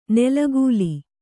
♪ nelagūli